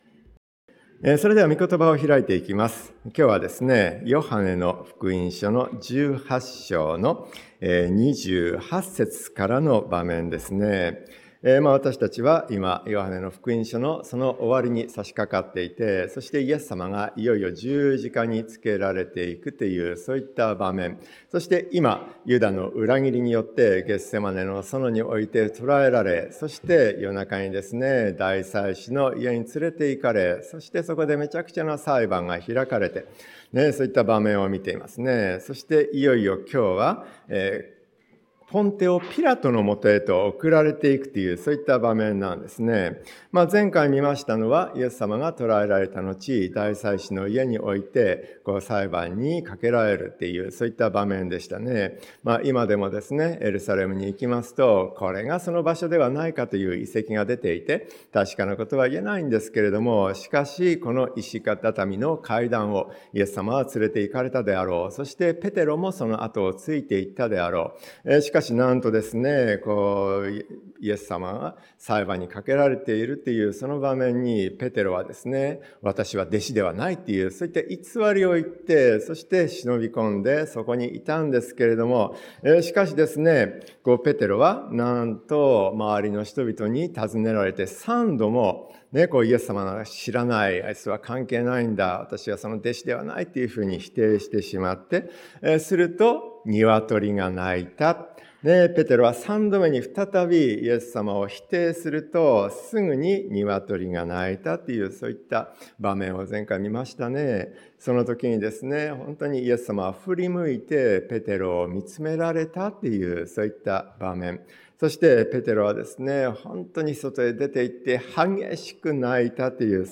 ピラトのもとに苦しみを受け 説教者